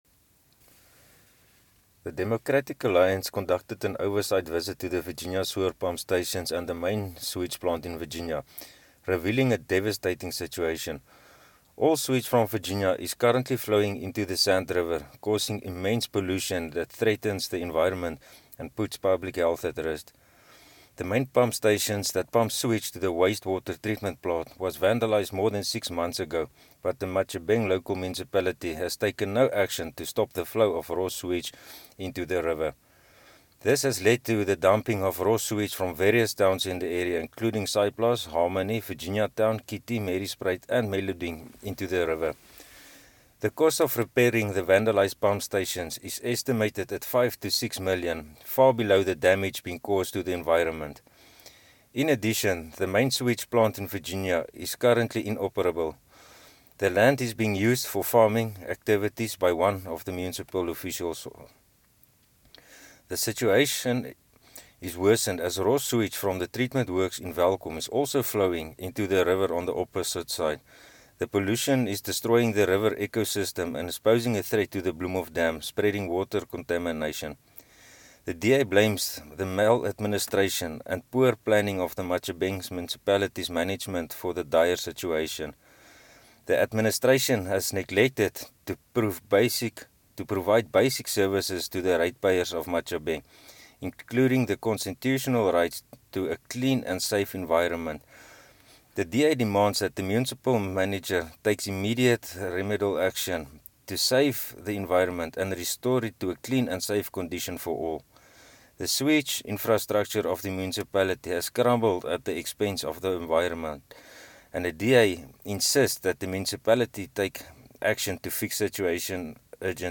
Afrikaans soundbites by Cllr Hansie du Plessis and